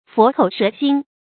佛口蛇心 fó kǒu shé xīn 成语解释 比喻话虽说得好听，心肠却极狠毒。
成语繁体 佛口虵心 成语简拼 fksx 成语注音 ㄈㄛˊ ㄎㄡˇ ㄕㄜˊ ㄒㄧㄣ 常用程度 常用成语 感情色彩 贬义成语 成语用法 联合式；作补语、定语；比喻嘴甜心毒 成语结构 联合式成语 产生年代 古代成语 近 义 词 口蜜腹剑 反 义 词 菩萨心肠 、 佛口圣心 成语例子 我面貌虽丑，心地却是善良，不似你 佛口蛇心 。